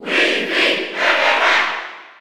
Category:Crowd cheers (SSB4) You cannot overwrite this file.
Wii_Fit_Trainer_Cheer_Spanish_PAL_SSB4.ogg